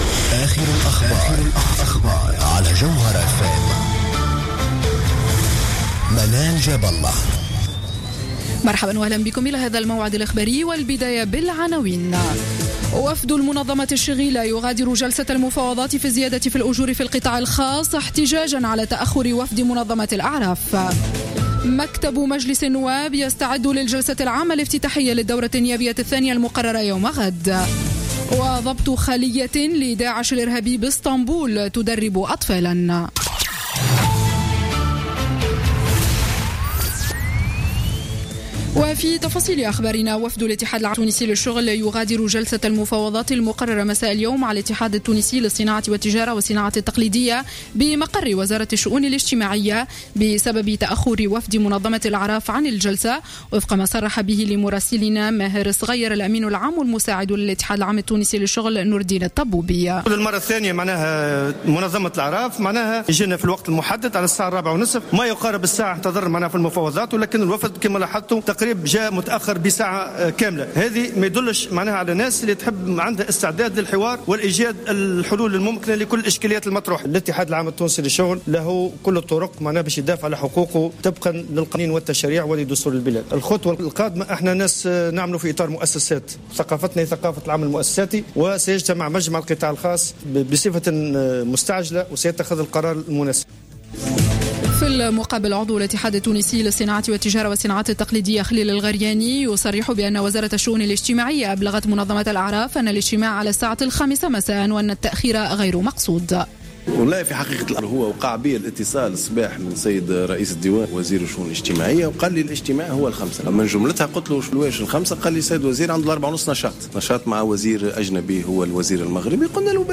نشرة أخبار الساعة 19.00 ليوم الإثنين 19 أكتوبر 2015